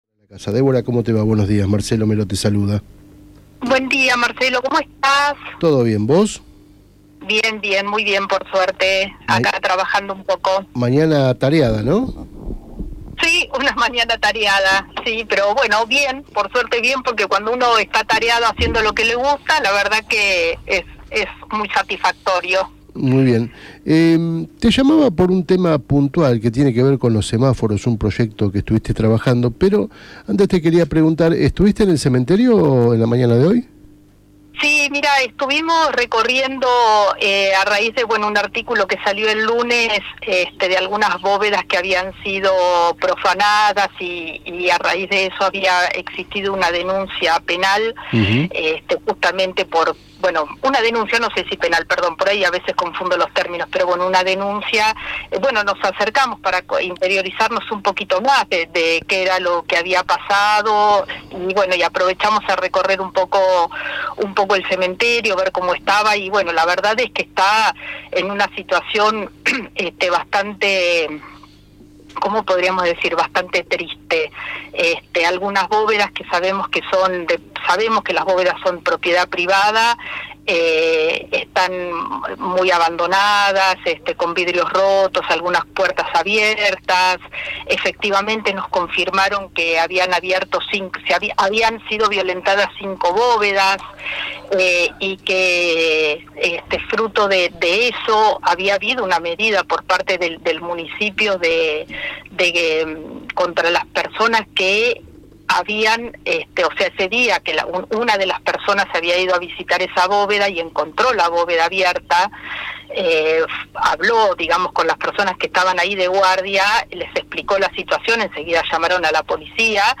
Escuchá la entrevista completa a Débora Lacasa: